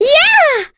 Cleo-Vox_Happy6.wav